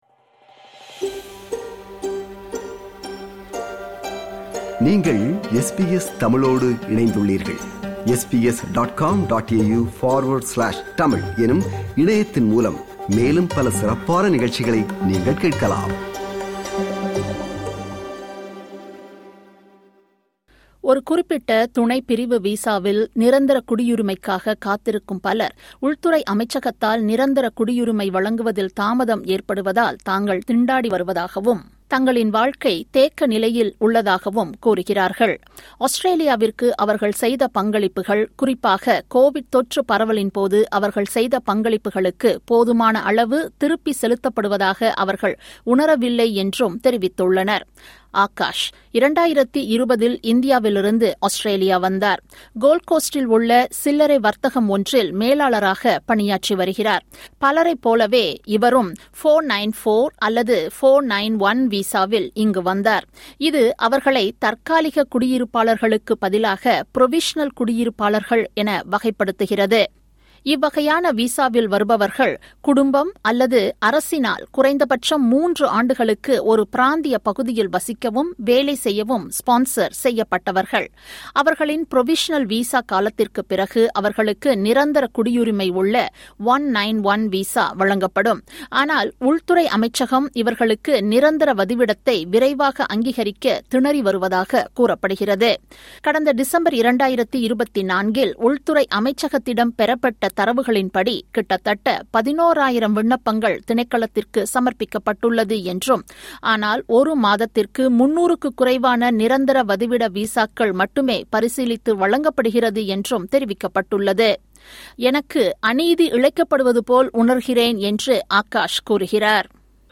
விளக்குகிறது இந்த விவரணம்.